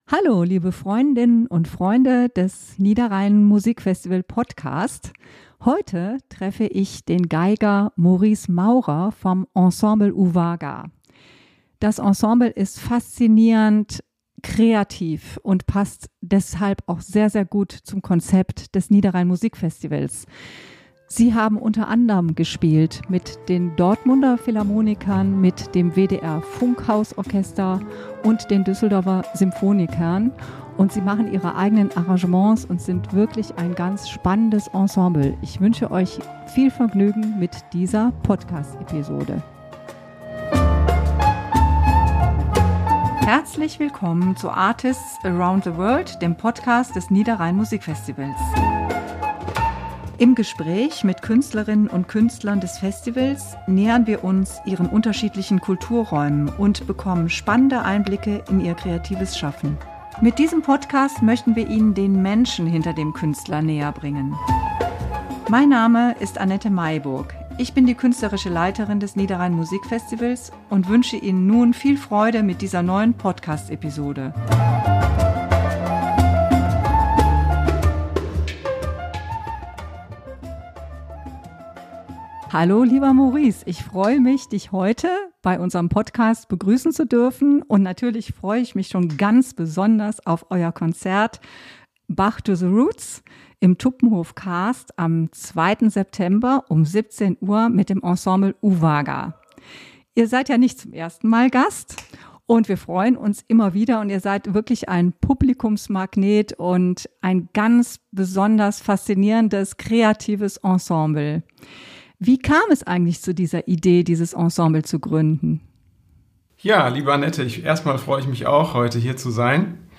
Interview ~ Artists around the world - Der Podcast zum Niederrhein Musikfestival Podcast